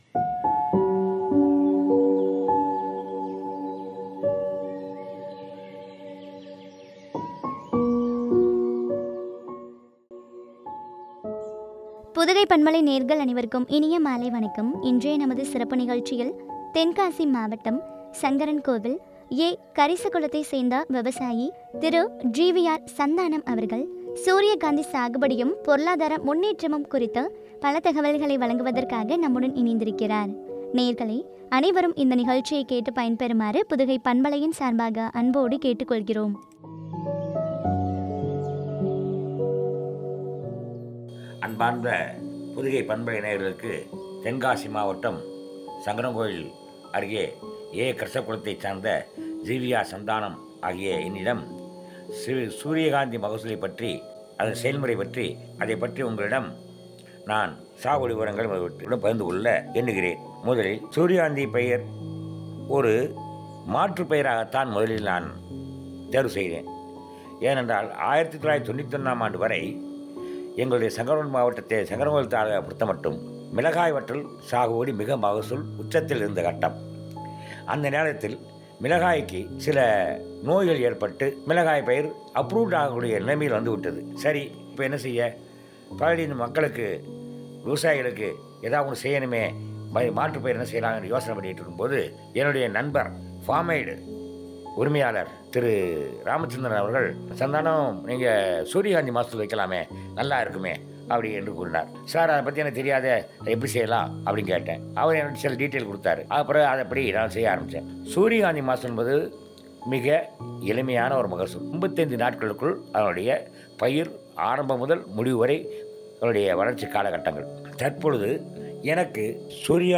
பொருளாதார முன்னேற்றமும் பற்றிய உரையாடல்.